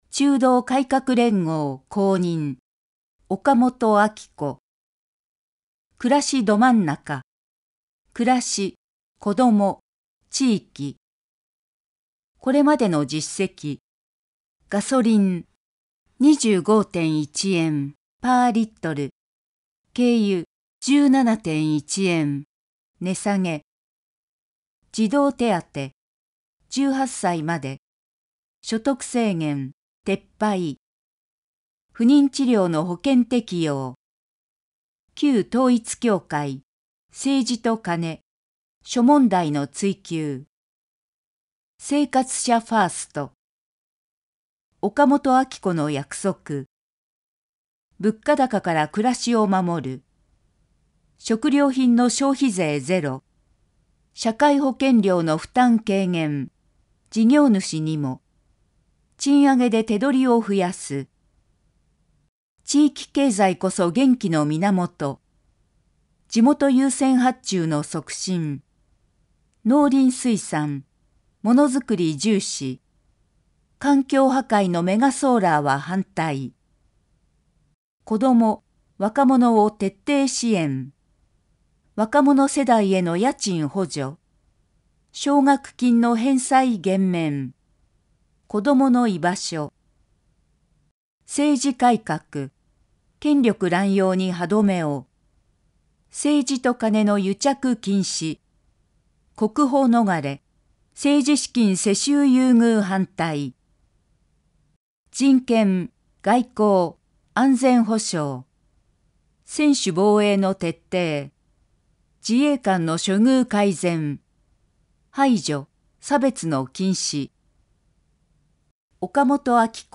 衆議院議員総選挙候補者・名簿届出政党等情報（選挙公報）（音声読み上げ用）